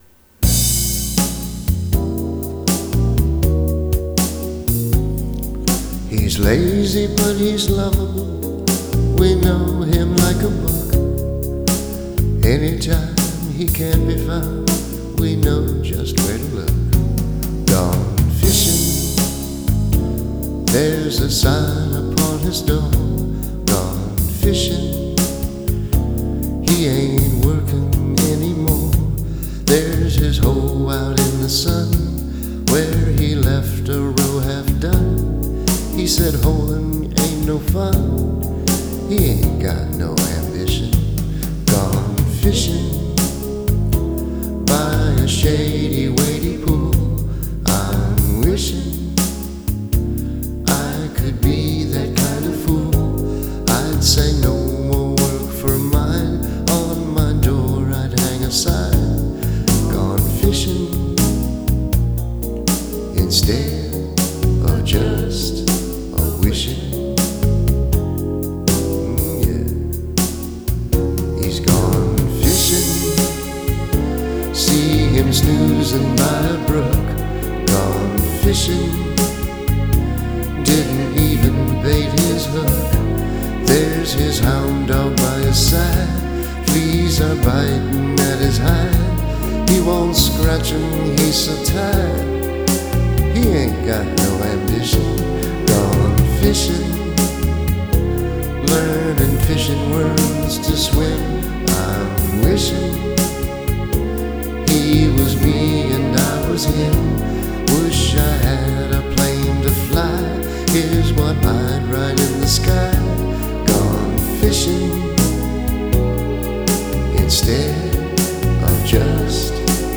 backup vocals